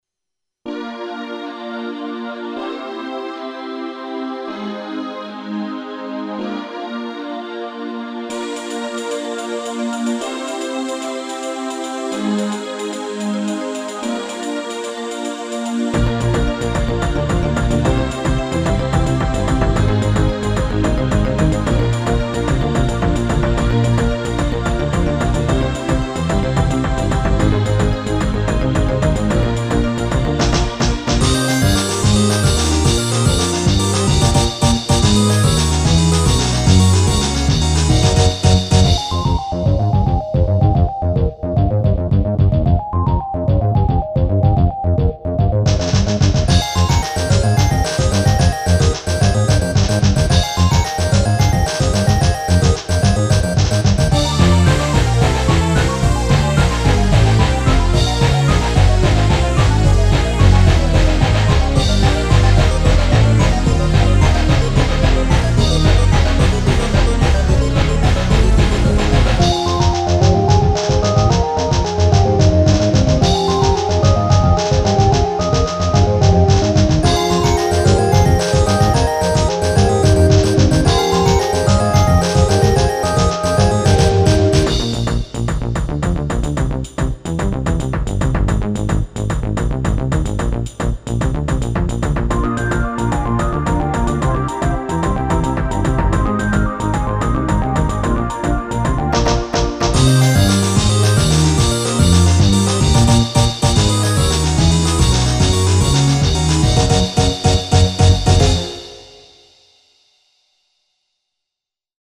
思い付きで悪ノリして終始7拍子で書きました。